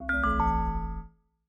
steeltonguedrum_c1ae.ogg